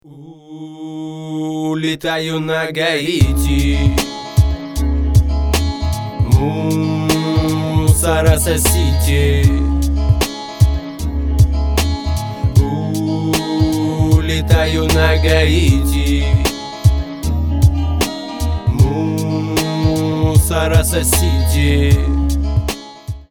Громкая танцевальная песня доступна бесплатно.